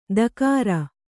♪ dakāra